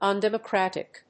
音節un・dem・o・crat・ic 発音記号・読み方
/`ʌndèməkrˈæṭɪk(米国英語), ˌʌndemʌˈkrætɪk(英国英語)/